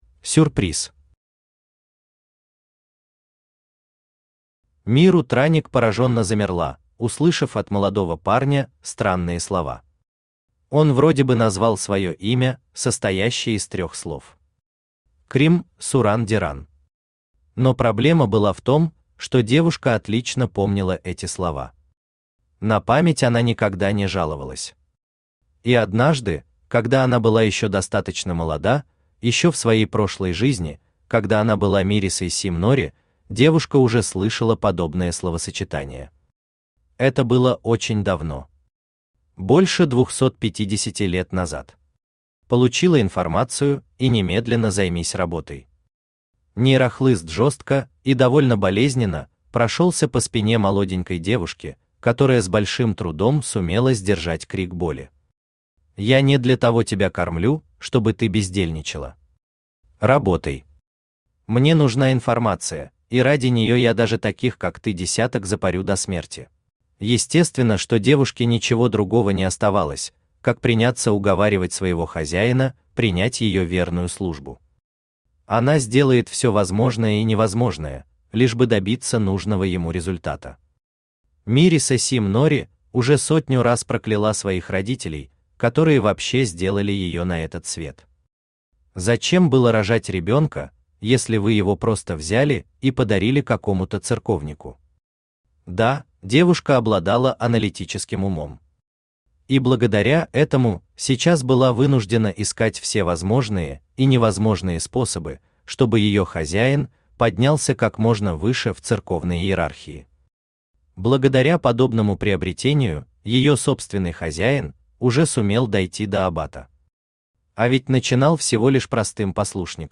Аудиокнига Дикарь. Часть 2. Бег по кругу | Библиотека аудиокниг
Бег по кругу Автор Хайдарали Усманов Читает аудиокнигу Авточтец ЛитРес.